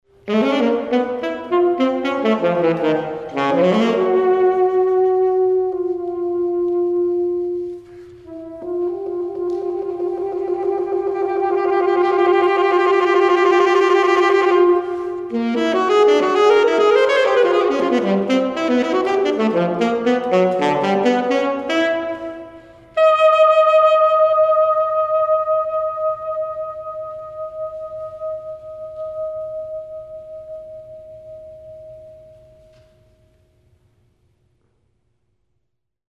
Saxophone solo